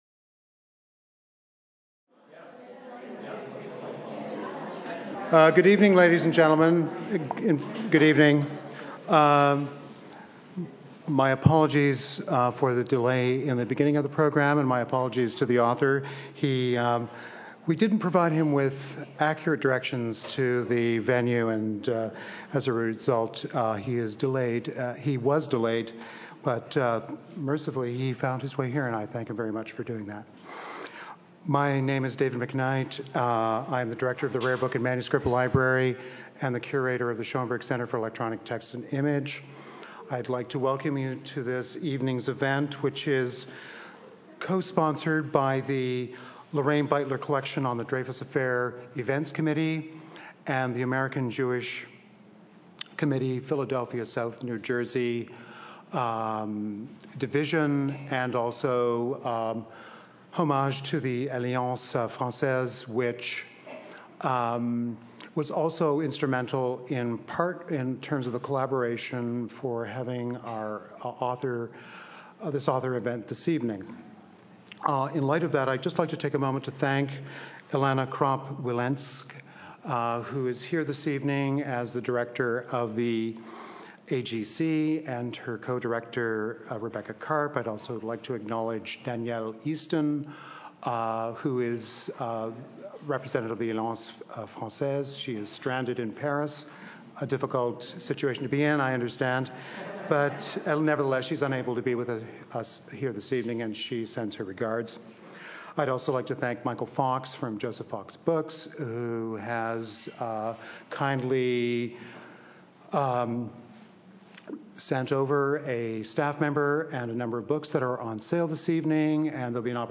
Novelist and lawyer Louis Begley, author of Wartime Lies, About Schmidt, and Matters of Honor, presents his book Why the Dreyfus Affair Matters (Yale University Press, 2009). Begley investigates the abuses of judicial and military power that led to the persecution of Dreyfus for treason in 1894 and caused bitter divisions in French society for years afterward.